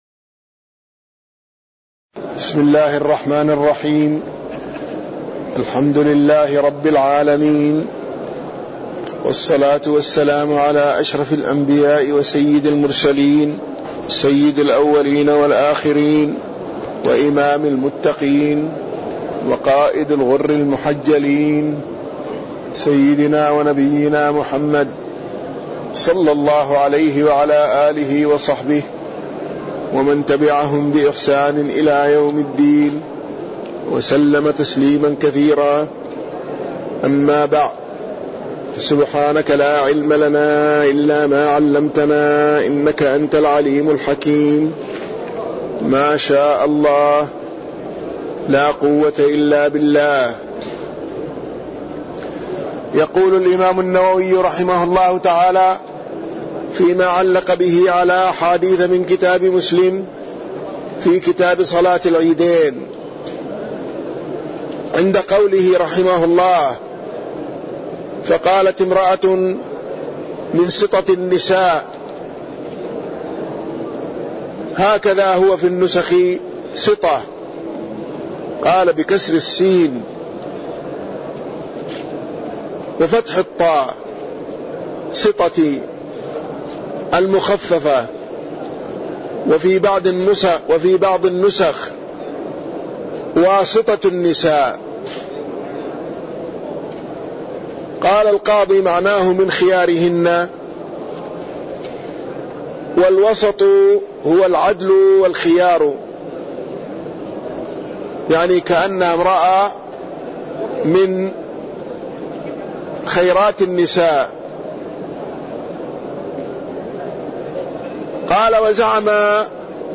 تاريخ النشر ١٧ شوال ١٤٣١ هـ المكان: المسجد النبوي الشيخ